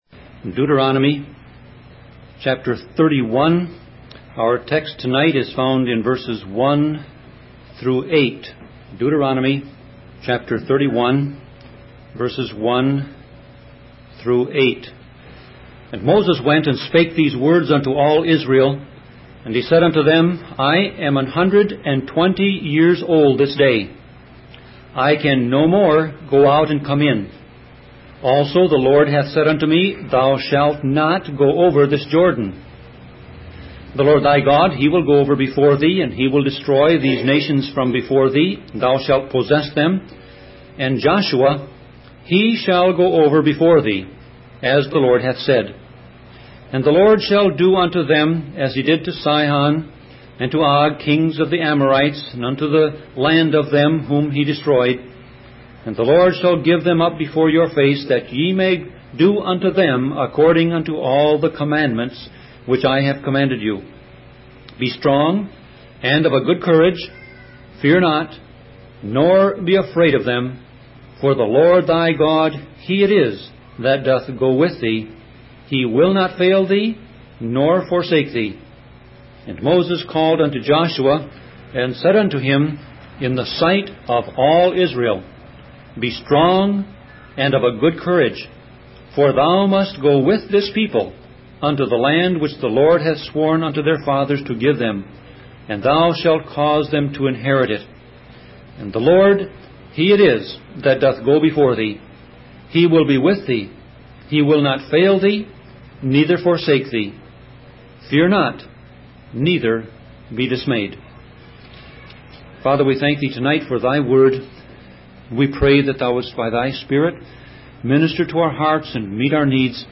Sermon Audio Passage: Deuteronomy 31:1-8 Service Type